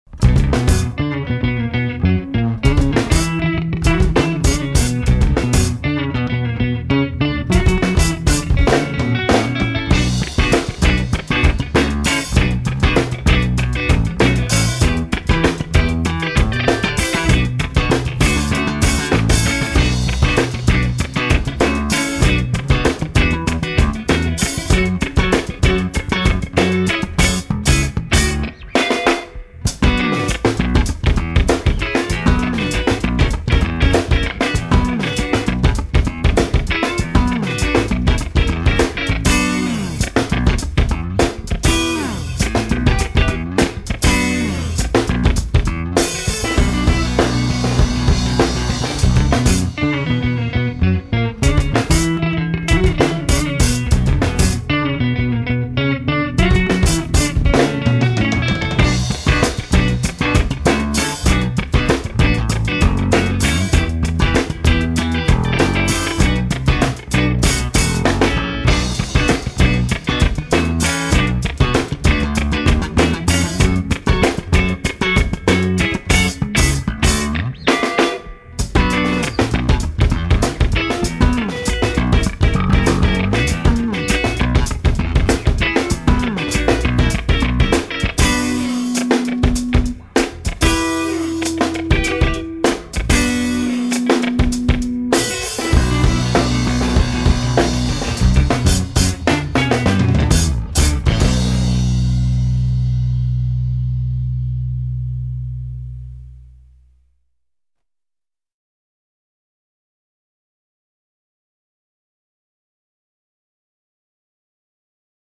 im Wki-Studio in Bad Münstereifel
Gitarre
Schlagzeug